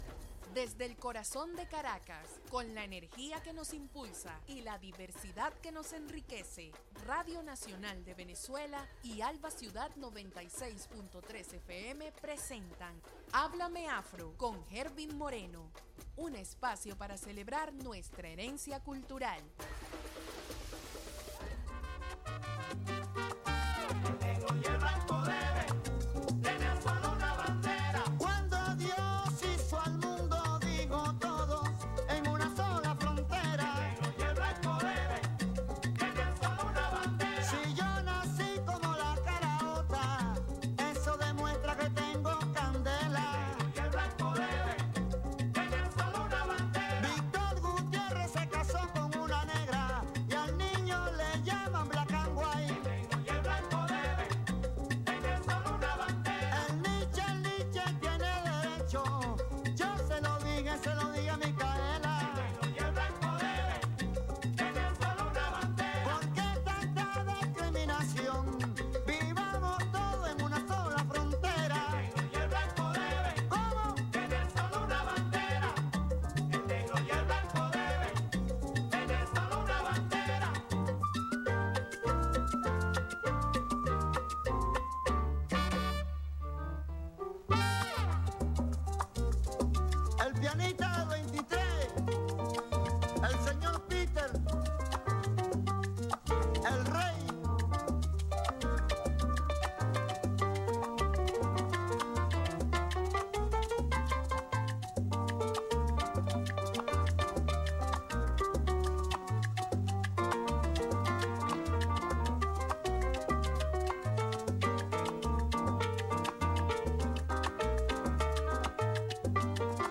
Visibiliza la cultura, historia y experiencias de las comunidades afrodescendientes. Educa y sensibiliza sobre las tradiciones y las luchas que han enfrentado estas comunidades a lo largo del tiempo. Transmitido en simultáneo con RNV.